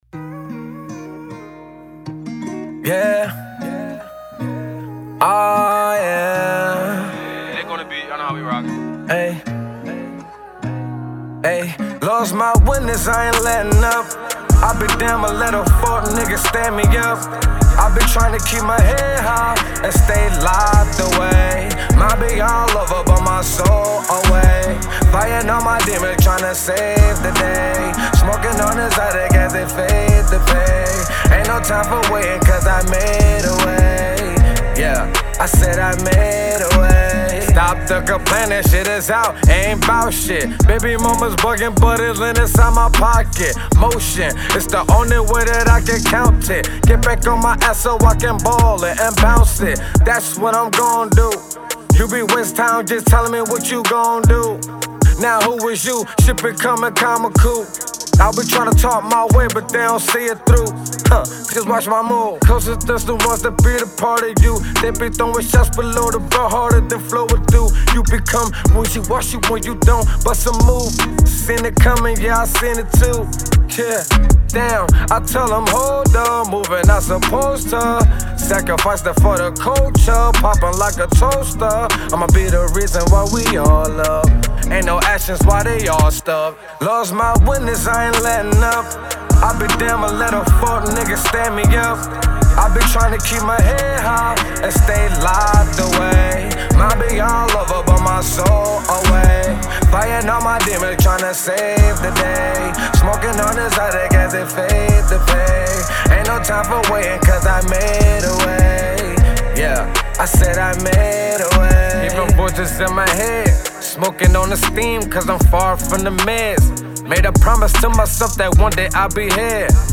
Rap Artist